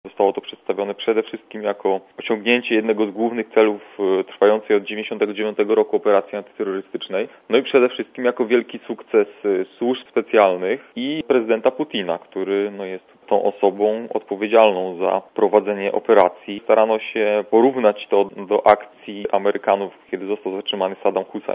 specjalista ds. Czeczeni